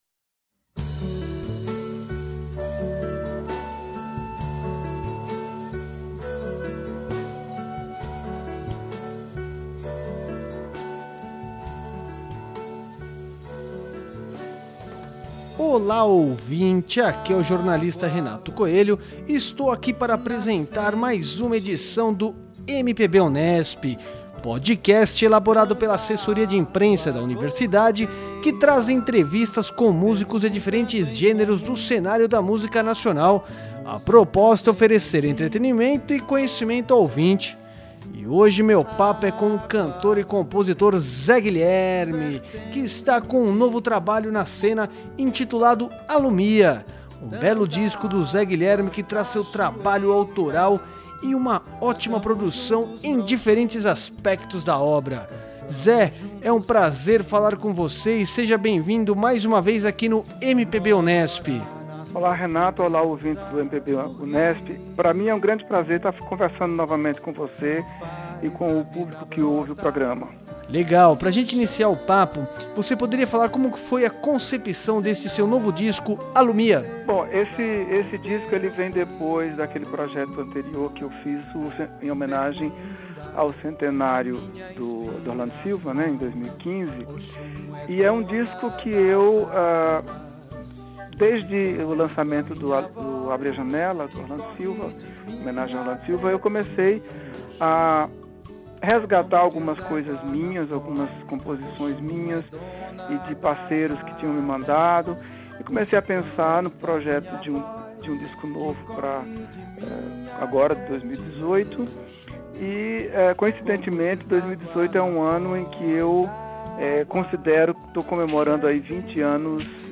Este Podcast Unesp conta com as canções "Alumia", "Oferendas" e "Canção de Você"
O PodMPB traz áudios de entrevistas com pesquisadores e músicos de diferentes gêneros, com a proposta de oferecer entretenimento e conhecimento ao ouvinte.